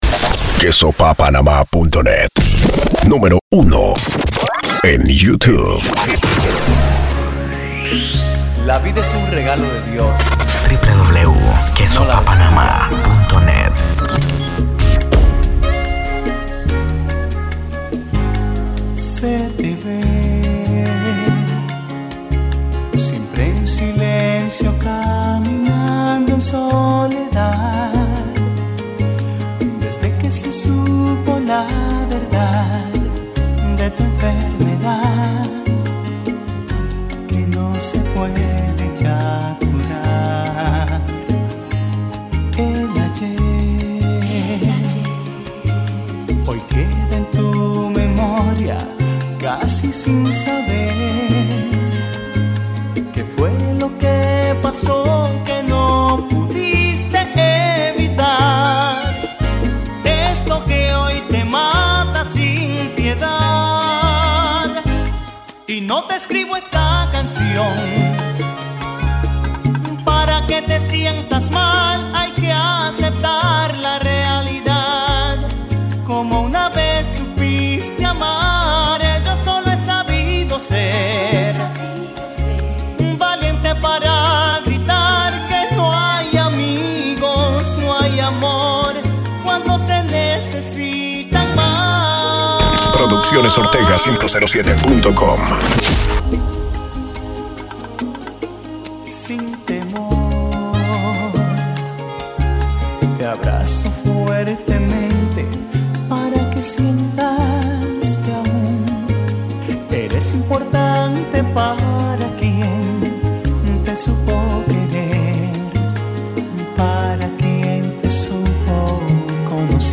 Mixes , Salsa